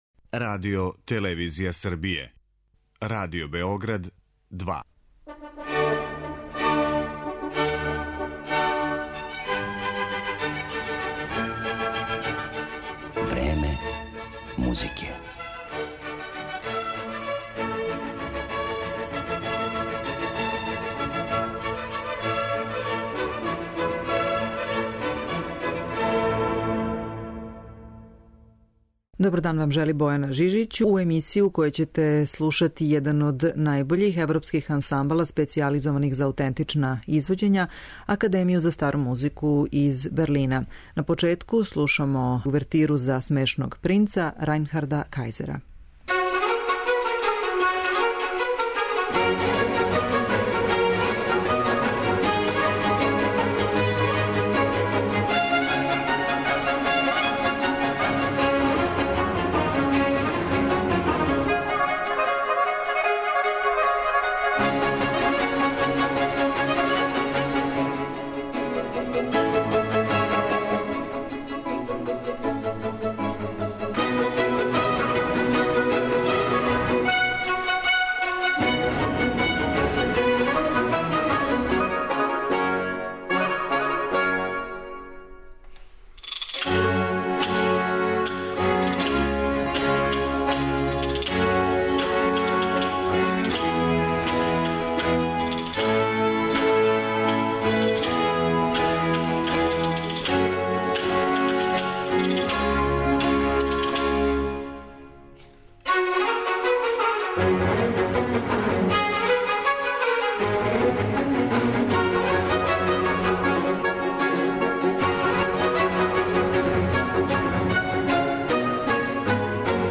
Био је то један од првих састава који је свирао на аутентичним инструментима и специјализовао се за барокну и ранокласичну музику у читавом некадашњем Источном блоку.